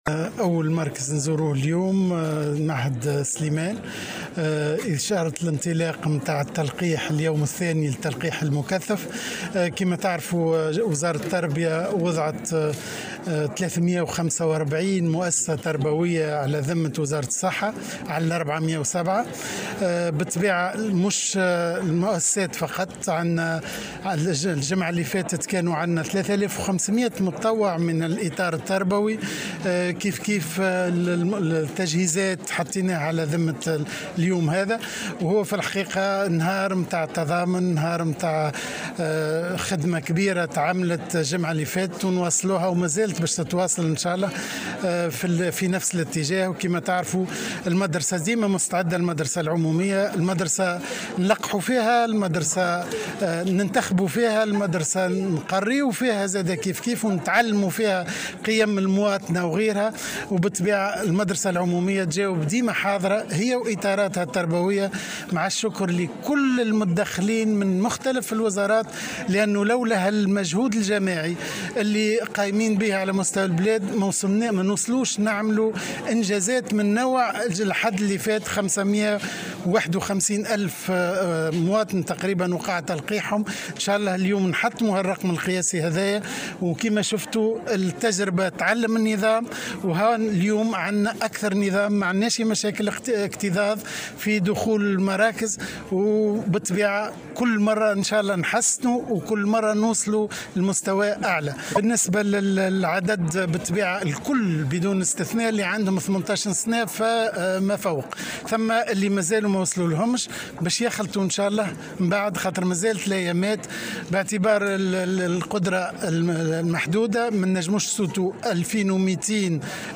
وأفاد فقي تصريح لمراسلة الجوهرة أف أم بنابل، اليوم الأحد، أن الفئة العمرية ما بين 15 و 18 سنة من التلاميذ سيتم النظر في امكانية تلقيحها بالتنسيق والتشاور مع اللجنة العلمية التي لها القرار النهائي.